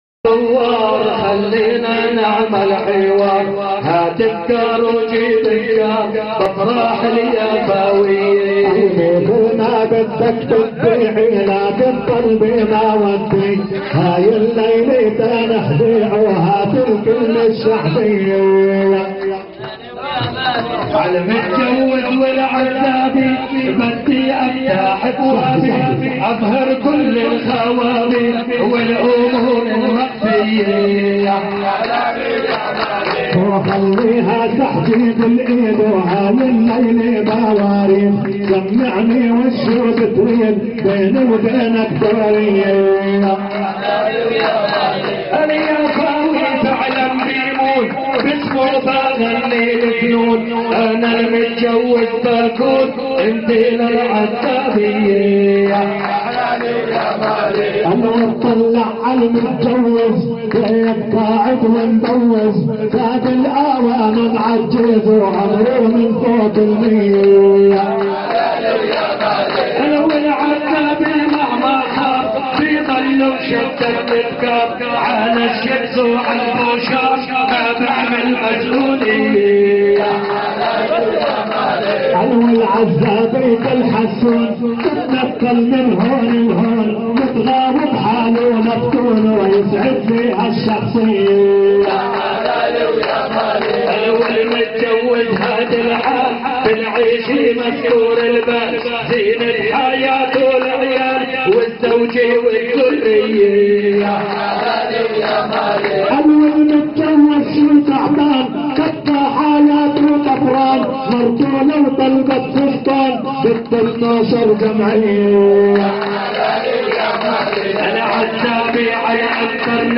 حداي ومحاورات